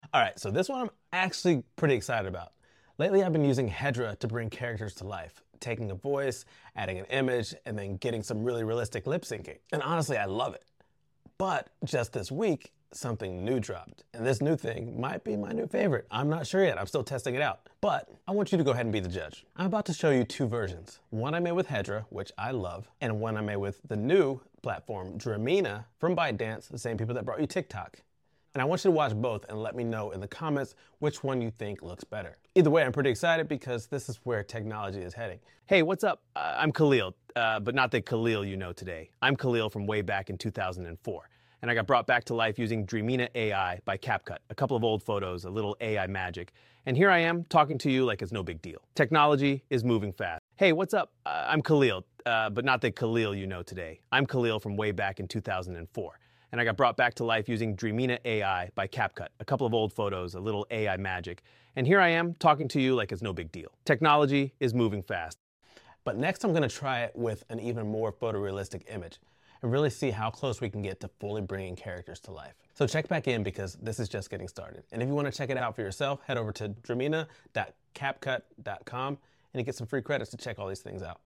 Same voice, same base image, different AI platforms.